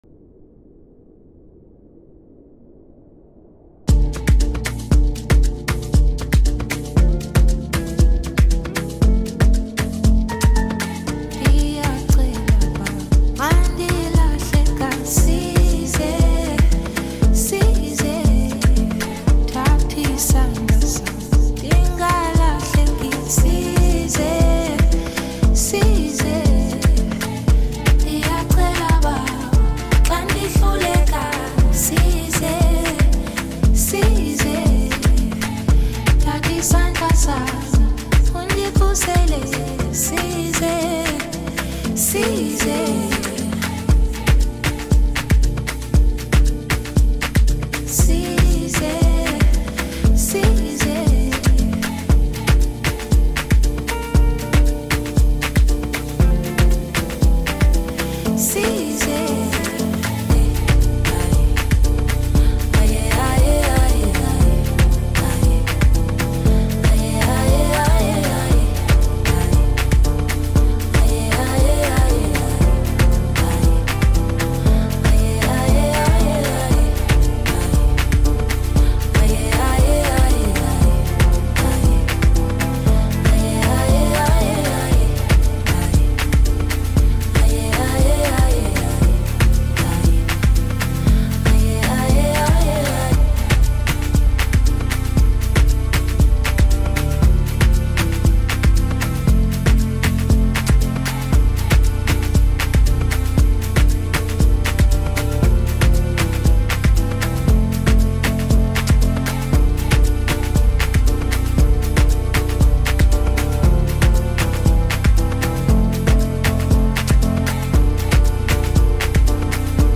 Rooted in Afro-house rhythms
emotive vocals
delivered with soulful intensity